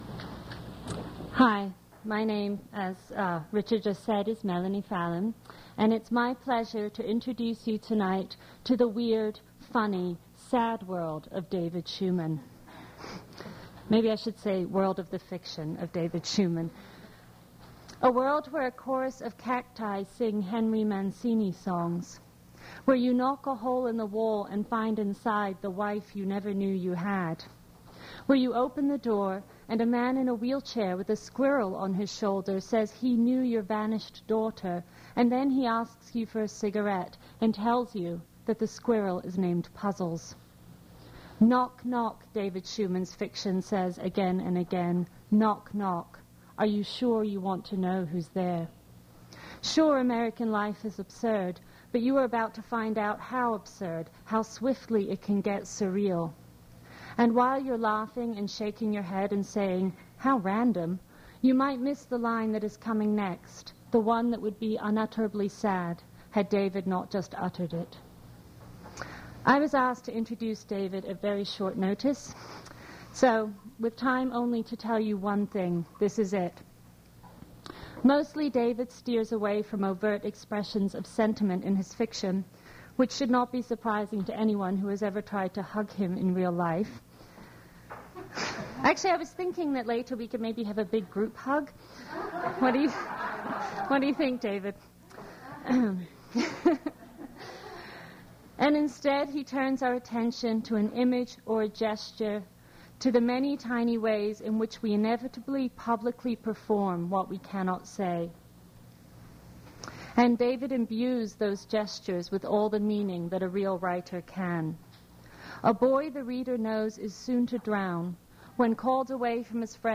prose reading
original audio cassette